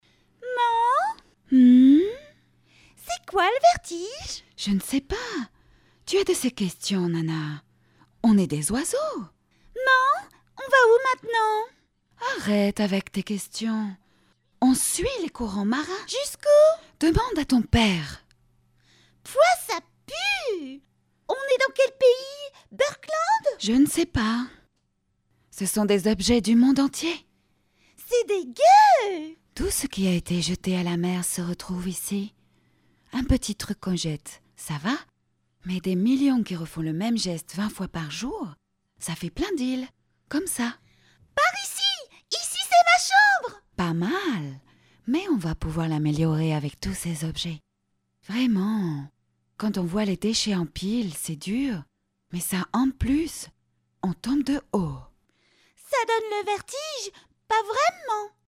Voix off
Bandes-son
Dessin animé : Maman Oiseau et son petit
- Mezzo-soprano